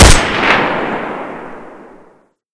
sa58_shoot.ogg